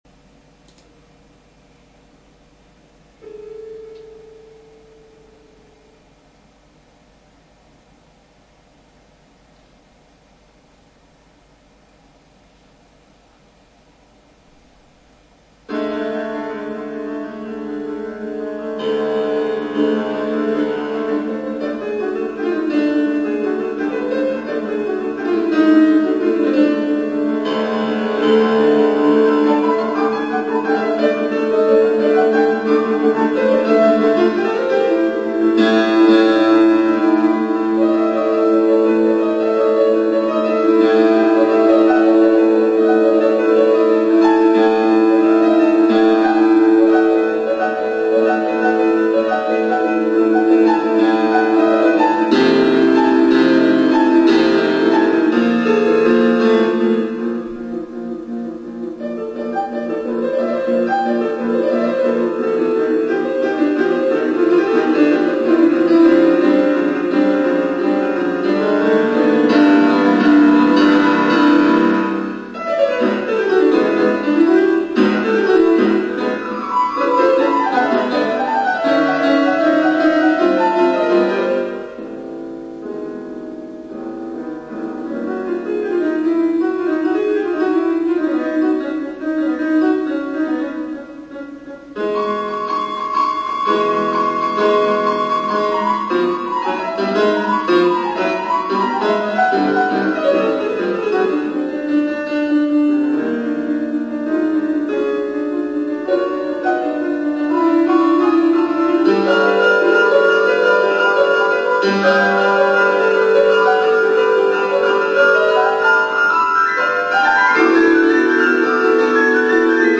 koncertje
zongorán
Részlet a koncertrõl...*.mp3